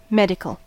Ääntäminen
Ääntäminen France: IPA: [me.di.kal] Haettu sana löytyi näillä lähdekielillä: ranska Käännös Ääninäyte Adjektiivit 1. medical US 2. med US Määritelmät Adjektiivit (courant) Relatif à la médecine .